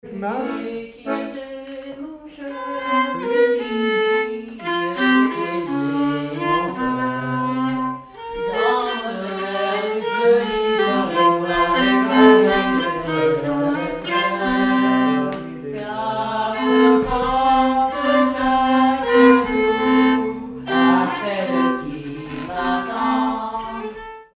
circonstance : bal, dancerie
Pièce musicale inédite